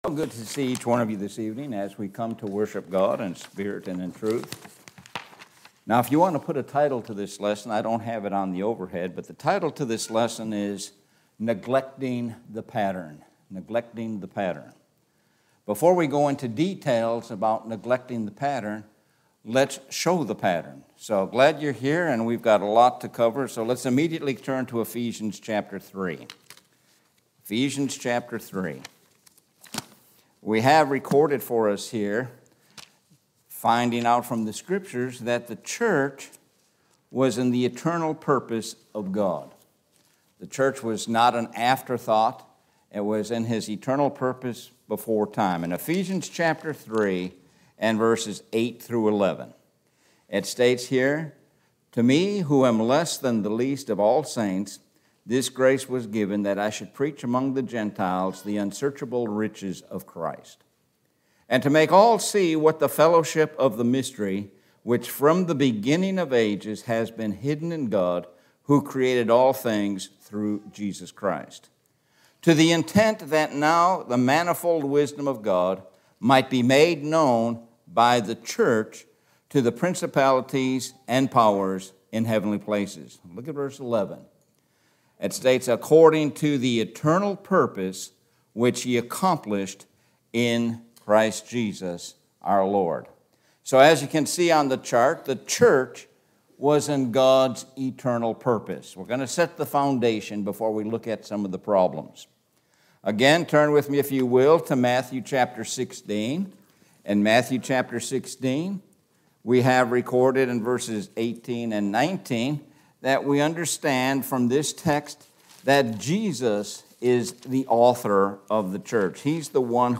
Sun PM Sermon – Neglecting the Pattern